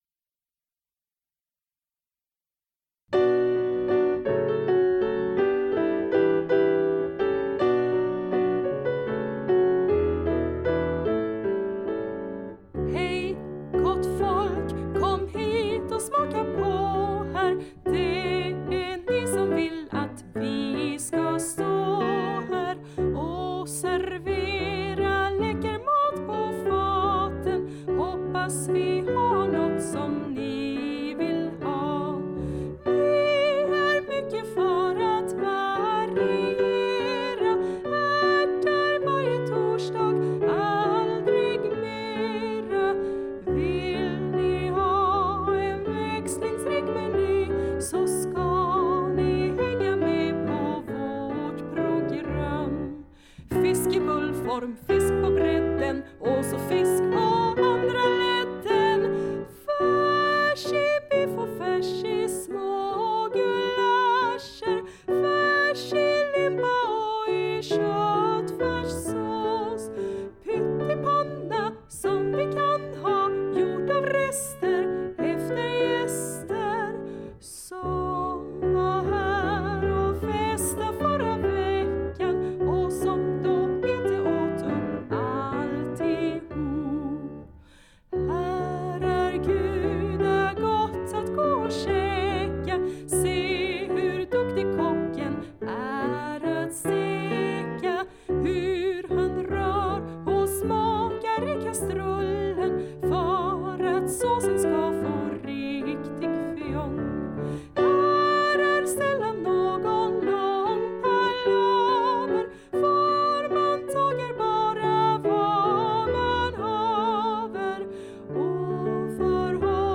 Musik och arrangemang och piano